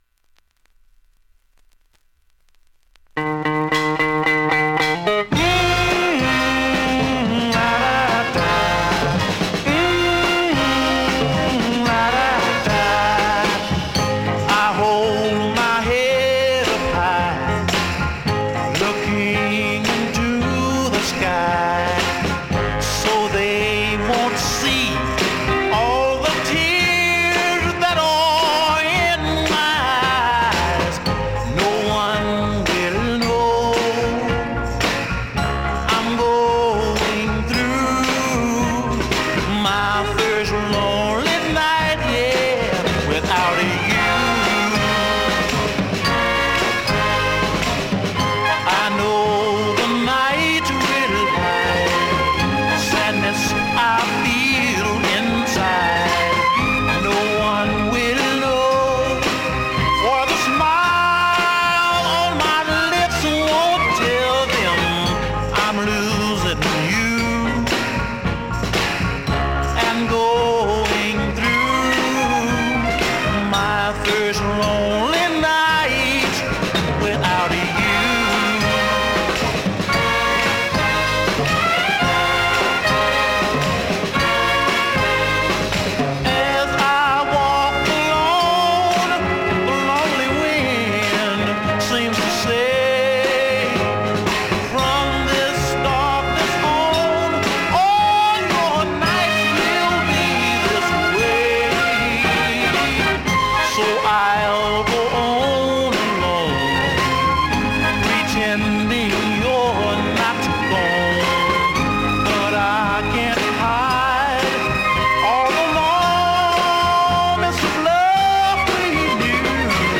現物の試聴（両面すべて録音時間5分18秒）できます。